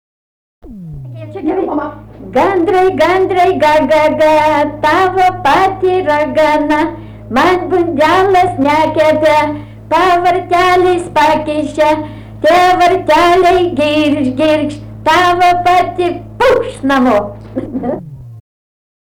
daina, vaikų